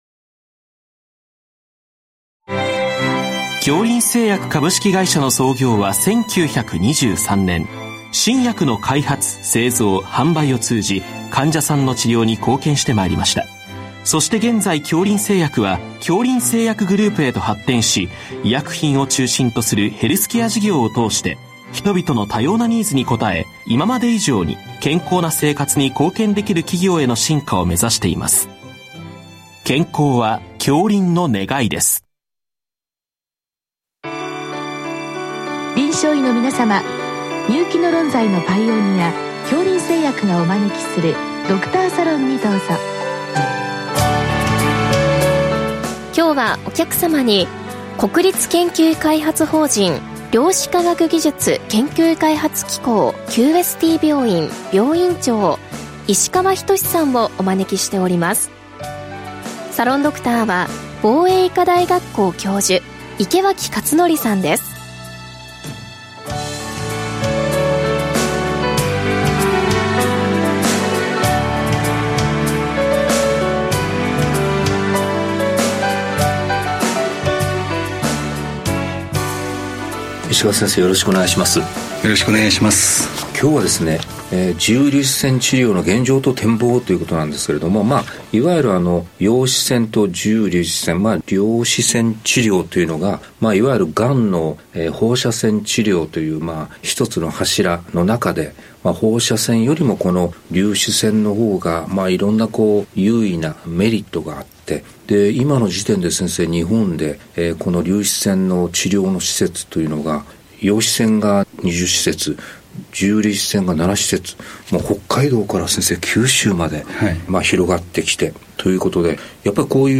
全国の臨床医の方々にむけた医学情報番組。臨床医の方々からよせられたご質問に、各分野の専門医の方々にご出演いただき、解説いただく番組です。